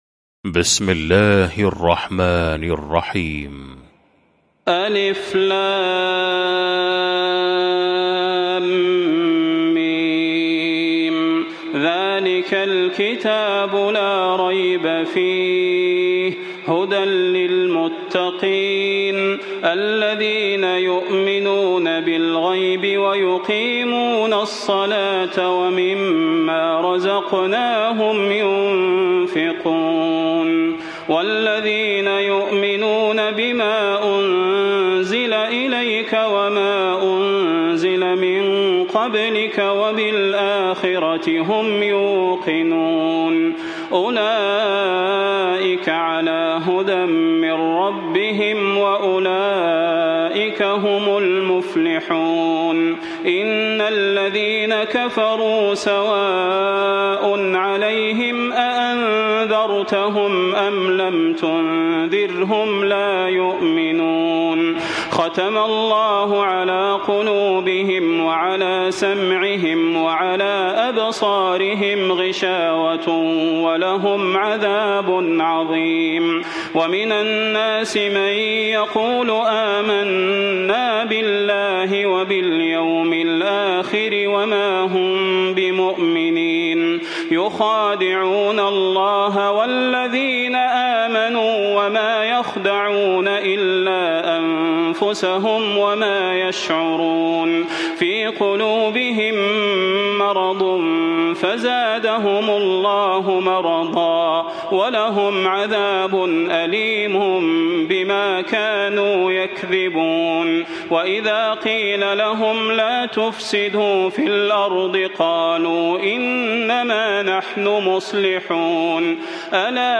المكان: المسجد النبوي الشيخ: فضيلة الشيخ د. صلاح بن محمد البدير فضيلة الشيخ د. صلاح بن محمد البدير البقرة